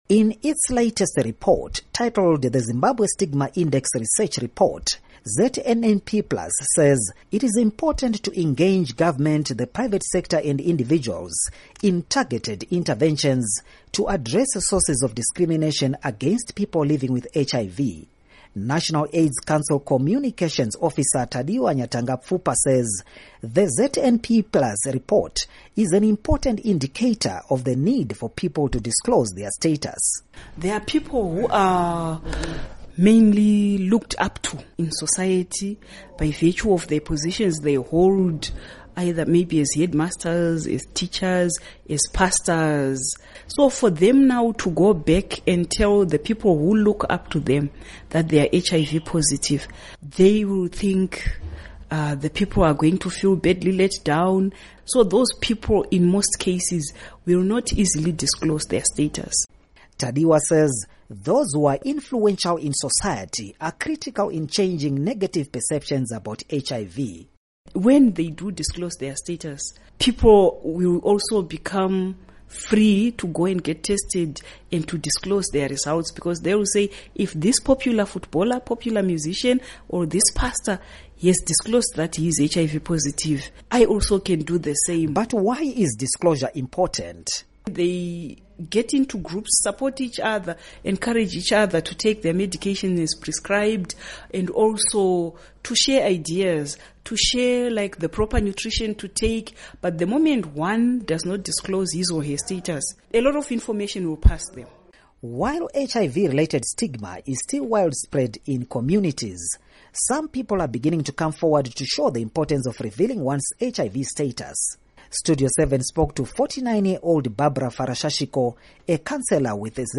Report on HIV Disclosure